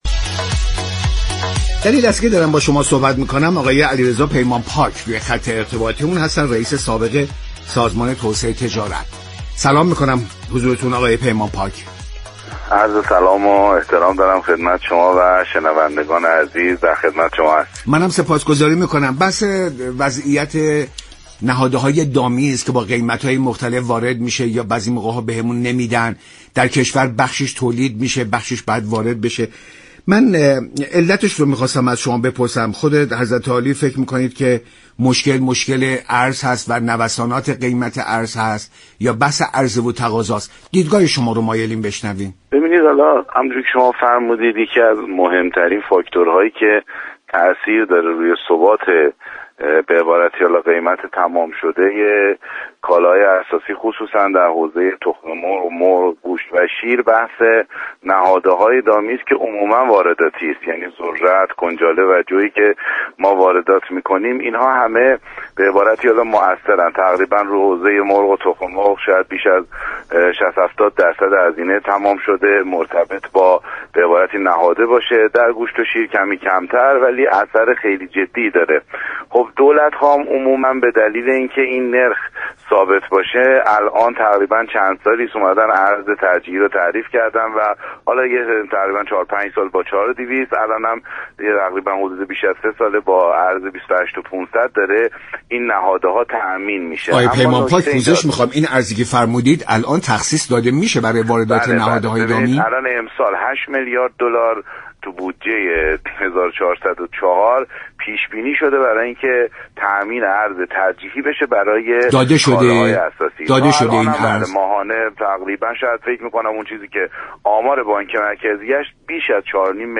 رییس سابق سازمان توسعه تجارت در برنامه ایران امروز گفت: نهاده‌های دامی 60 تا 70 درصد بر قیمت اقلام اساسی تاثیر جدی می‌گذارند.